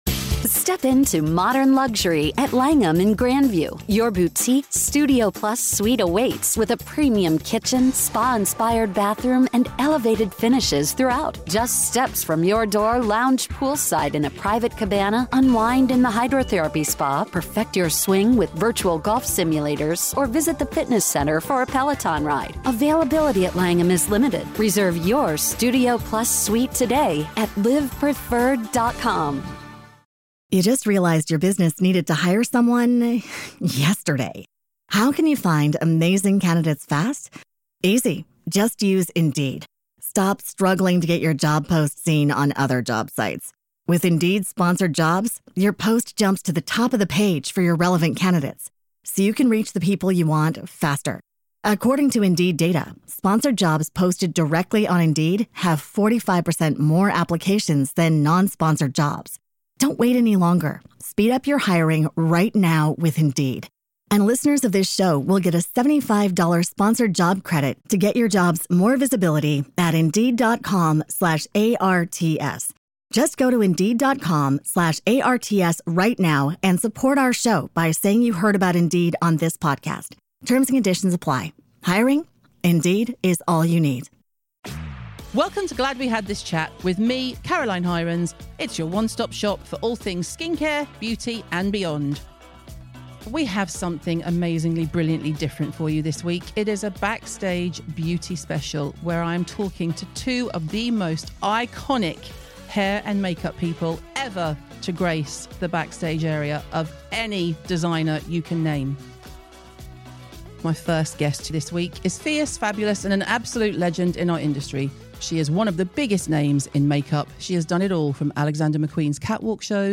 Presented By: Caroline Hirons Ad Details: Download the Skin Rocks App for free and sign up for Premium with your exlusive code CHPOD (all capitals), to save £10 off your annual subscription.